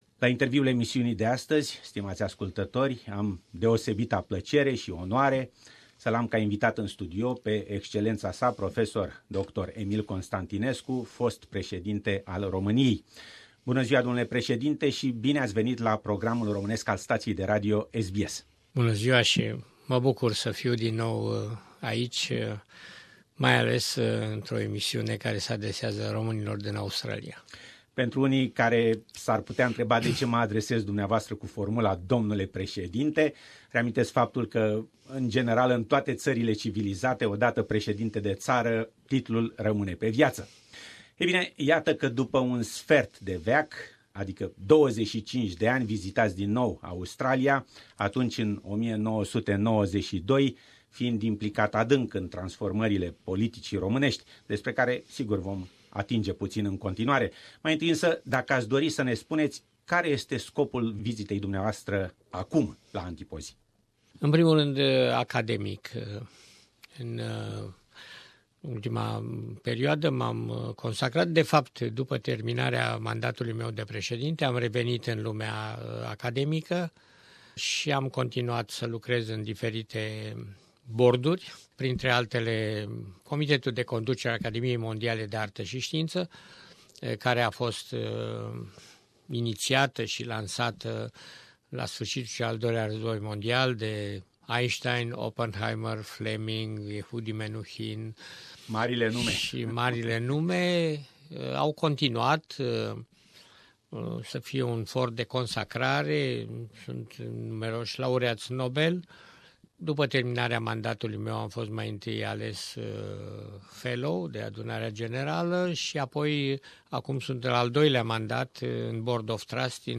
Interviu cu fostul Presedinte al Romaniei, Emil Constantinescu pt.1